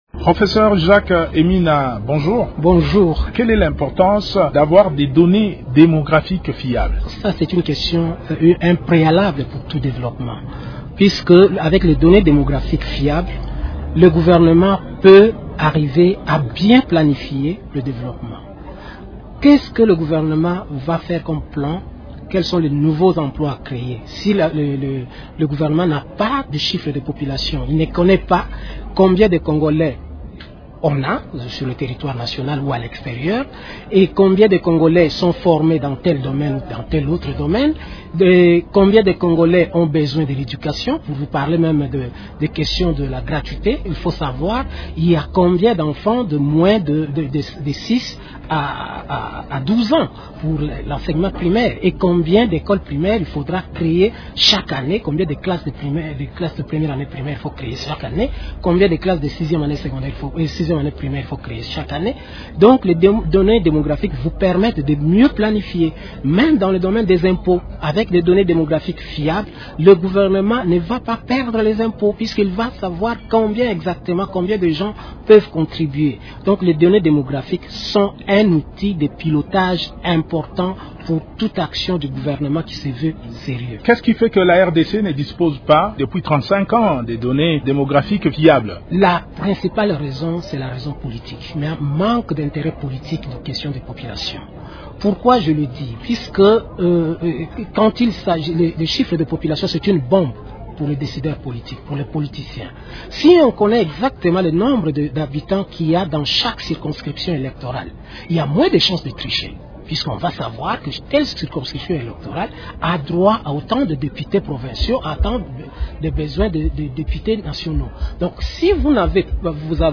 s’entretient sur ce sujet avec Albert Mpeti, vice-ministre de la santé publique (RDC).